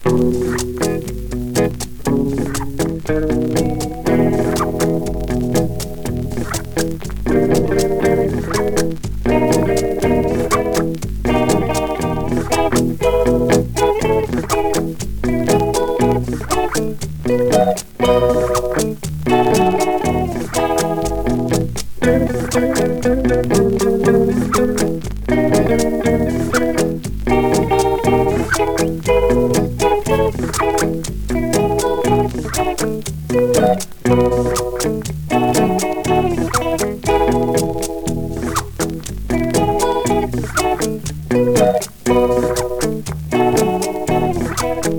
やわらかなイージーリスニング・ソフトロックンロールはリピート必至。